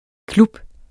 Udtale [ ˈklub ]